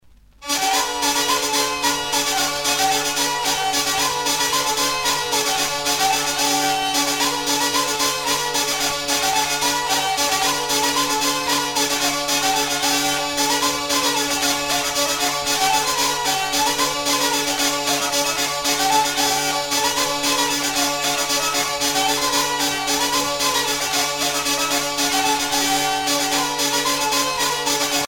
Suite de mazurkas
Région ou province Bourbonnais
danse : mazurka
Pièce musicale éditée